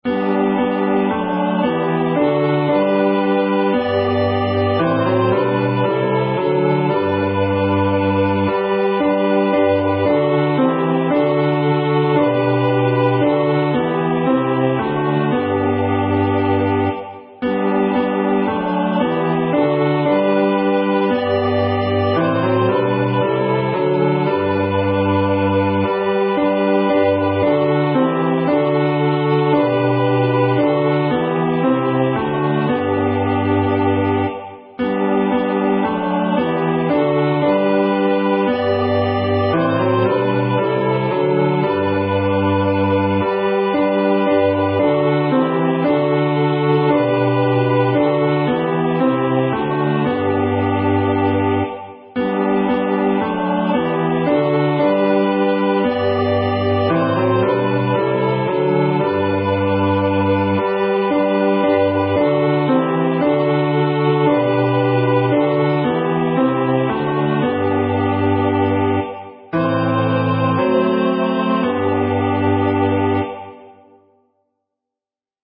Genre: SacredMotet